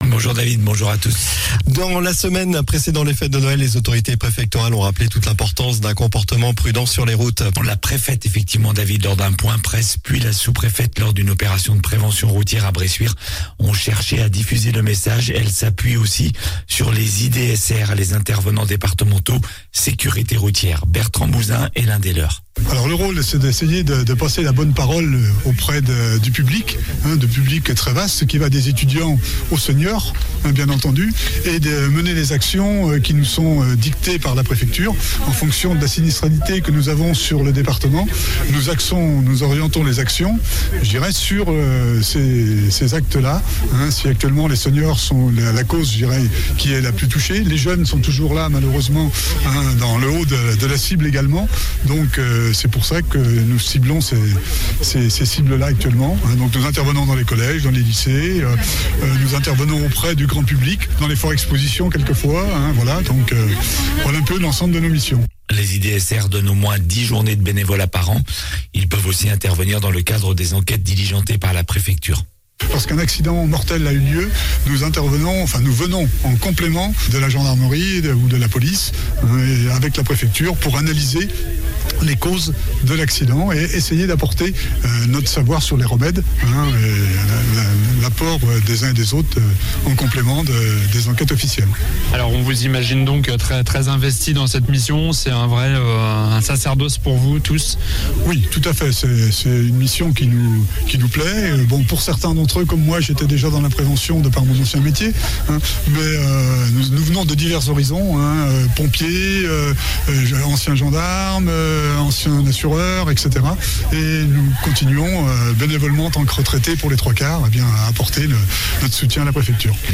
Journal du vendredi 23 décembre